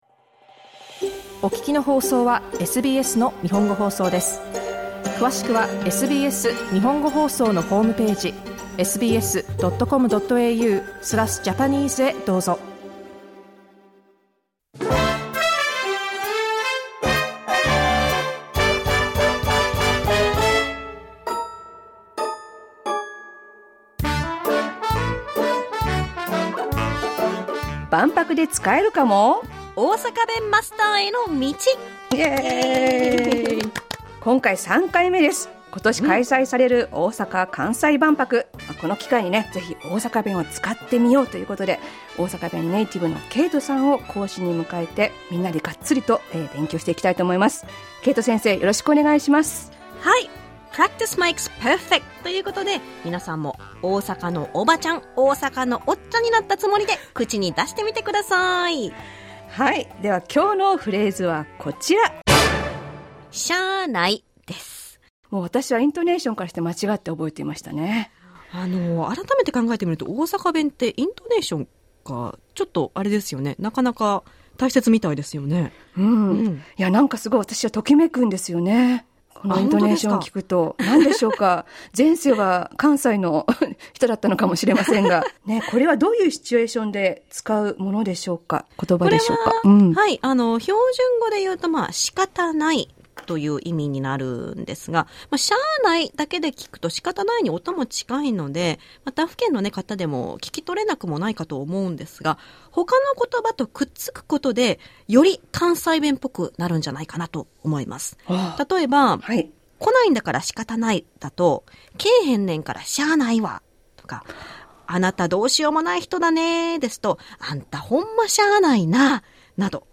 Let's speak Osaka dialect at EXPO 2025 Osaka, Kansai, Japan! SBS Japanese's segment to learn Osaka dialect.